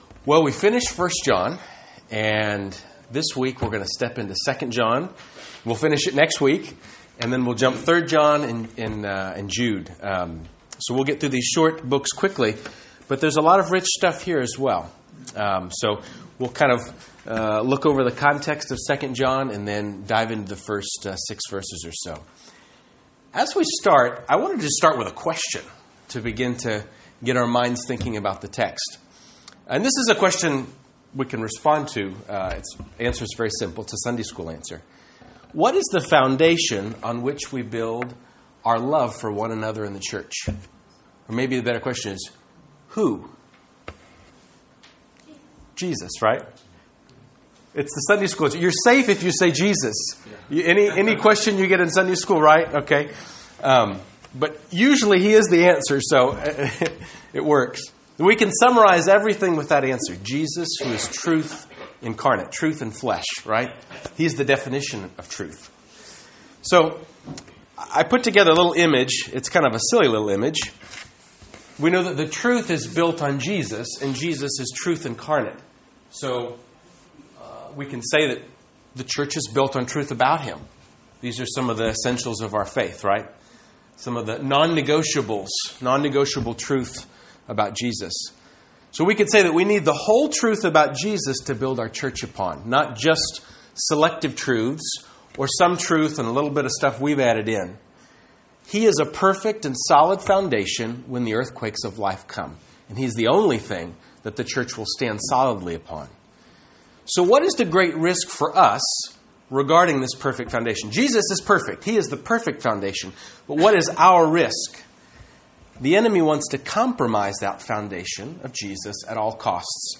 Service Type: English